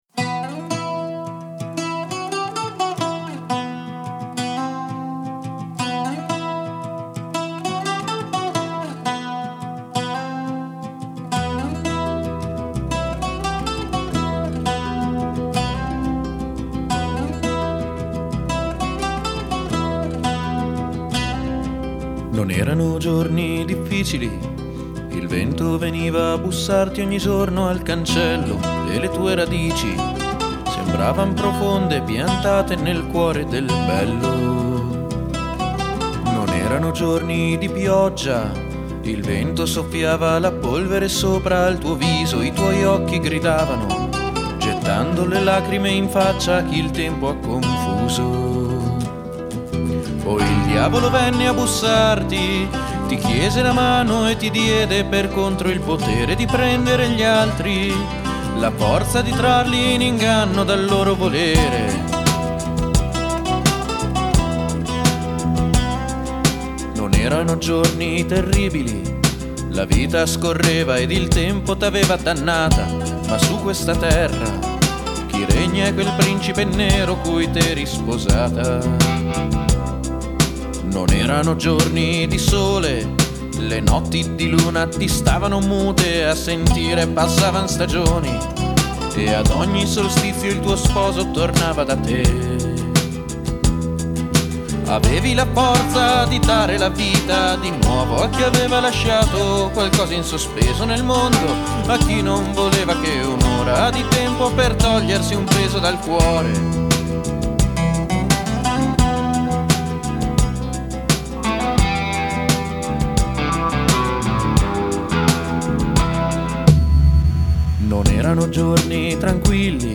GenereWorld Music / Folk